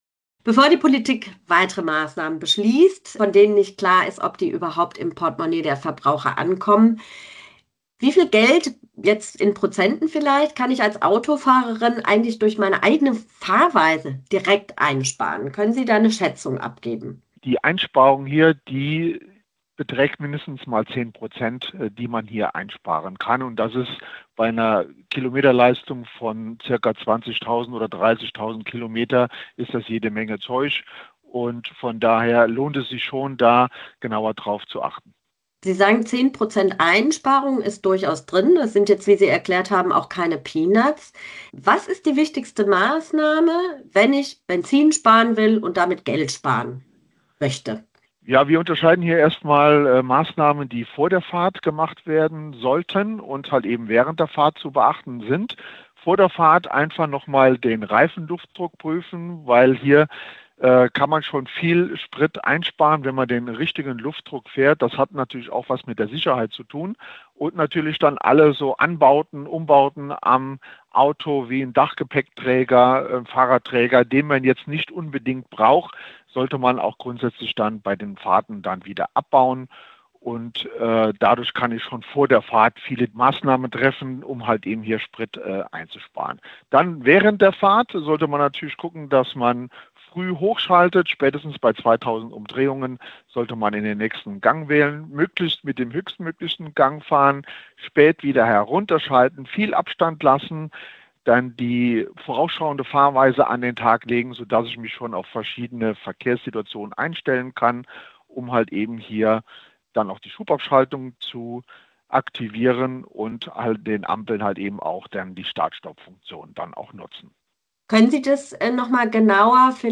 SWR Aktuell: Bevor die Politik weitere Maßnahmen beschließt, von denen nicht klar ist, ob die überhaupt im Portemonnaie der Verbraucher ankommen - können Sie eine Zahl nennen, wie viel Geld ich als Autofahrerin durch meine eigene Fahrweise direkt einsparen kann?